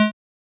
edm-perc-03.wav